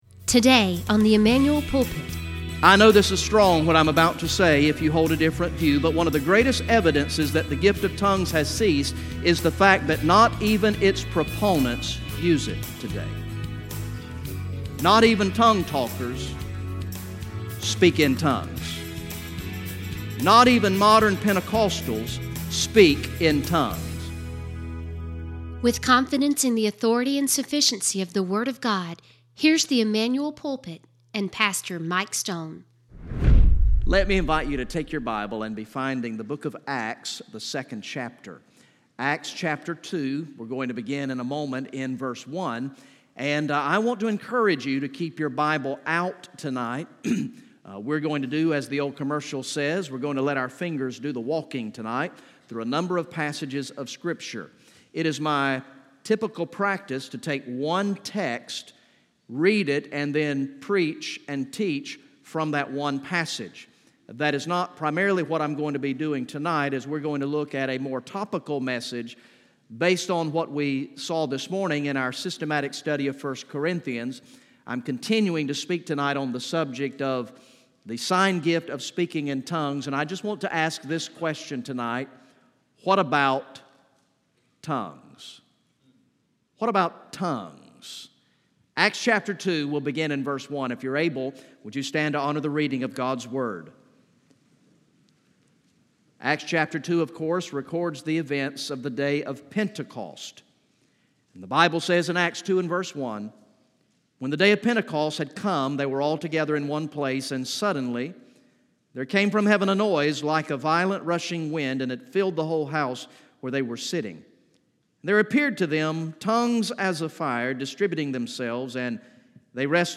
From the evening worship service on Sunday, October 28, 2018